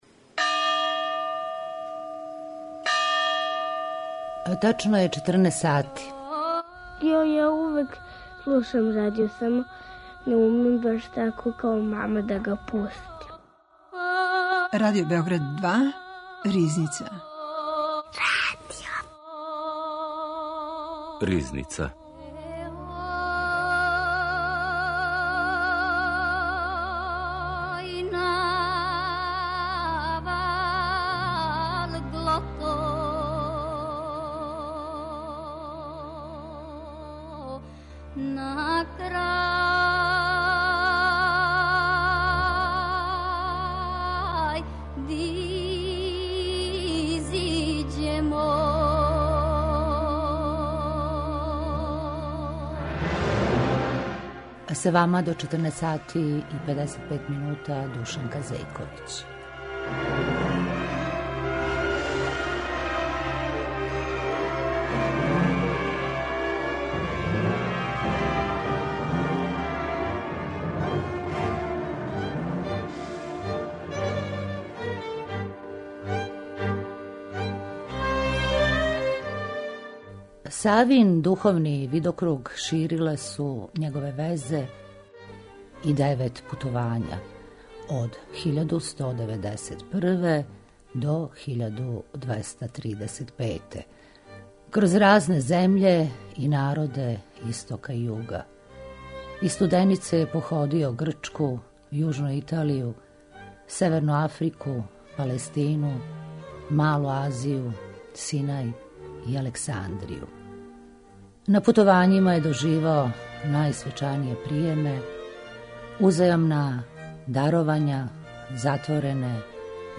Емисија